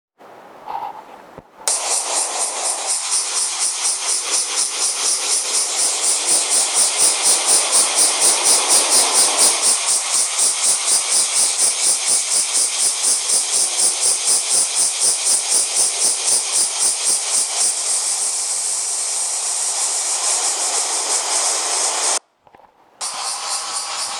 ここのところ聞こえてくるのは雨音ばかりでしたが、 今日は朝からセミが大合唱です。
セミの声を聴いてみよう 夏の音